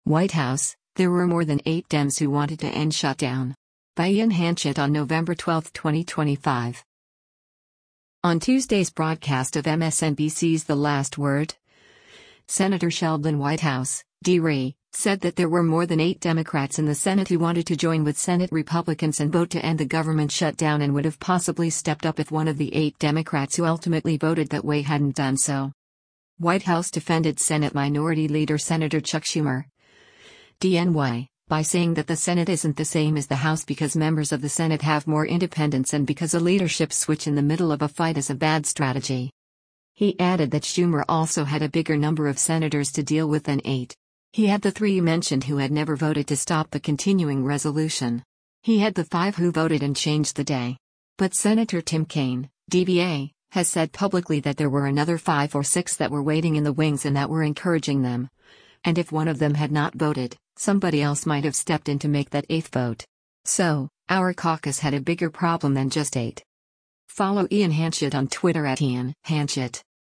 Video Source: MSNBC
On Tuesday’s broadcast of MSNBC’s “The Last Word,” Sen. Sheldon Whitehouse (D-RI) said that there were more than eight Democrats in the Senate who wanted to join with Senate Republicans and vote to end the government shutdown and would have possibly stepped up if one of the eight Democrats who ultimately voted that way hadn’t done so.